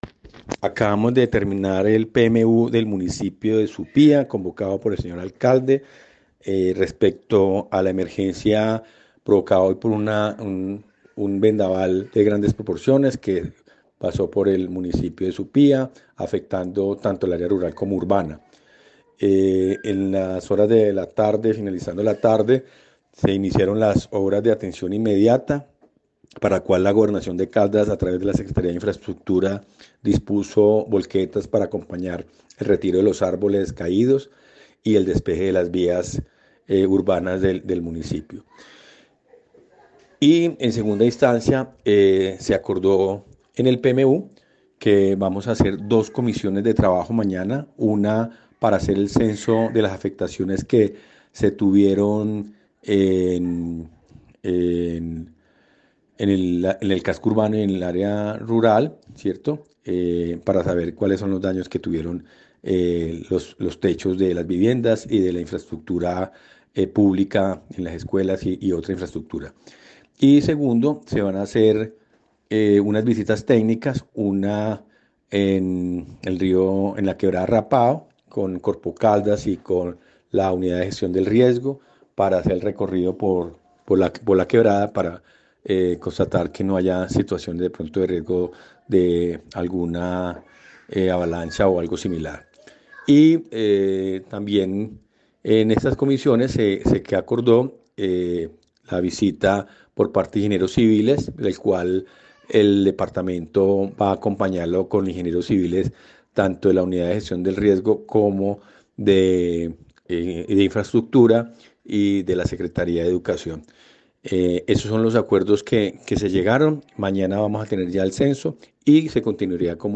Germán Alonso Páez Olaya, quien ocupa el cargo de jefe de la Unidad de Gestión del Riesgo de Caldas, informó sobre las labores en curso para afrontar esta emergencia.
German-Alonso-Paez-Olaya-jefe-de-la-Unidad-de-Gestion-del-Riesgo-de-Caldas.mp3